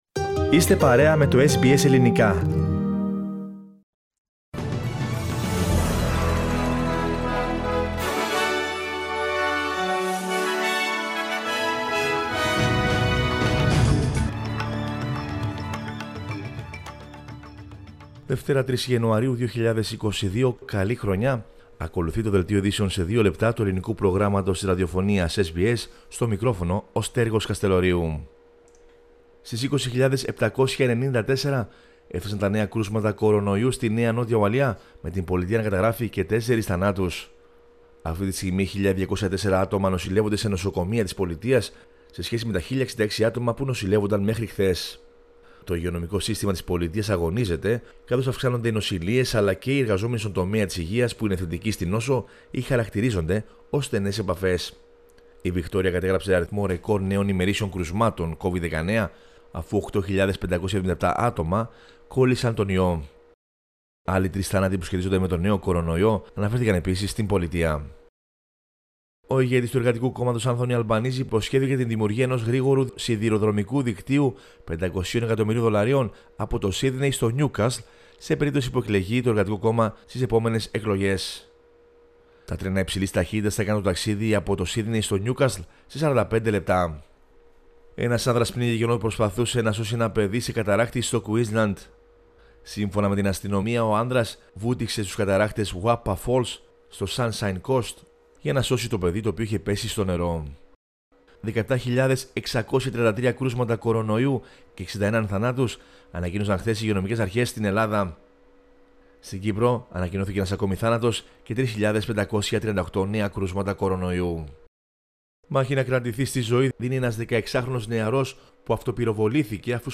News flash in Greek.